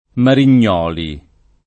[ marin’n’ 0 li ]